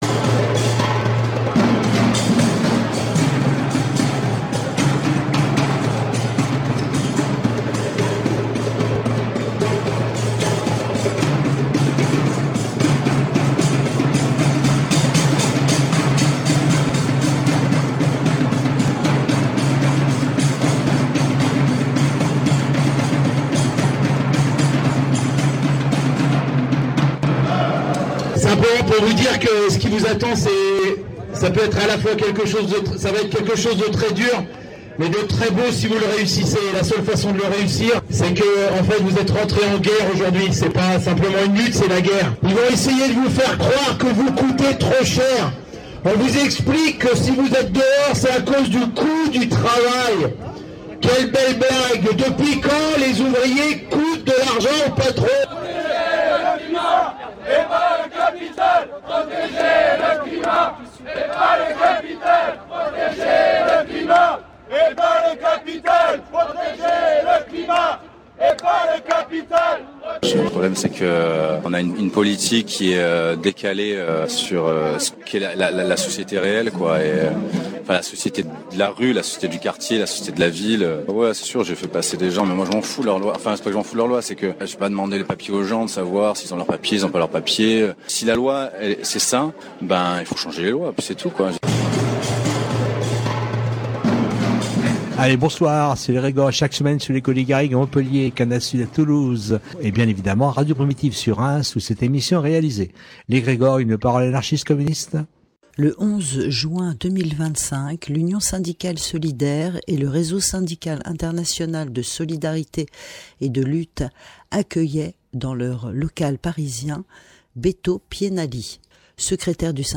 Il fut question de la situation sociale en Argentine, sous la présidence de Milei et comment le mouvement syndical argentin s’organise et agit. Nous vous proposons l’écoute d’une grande partie de cette rencontre.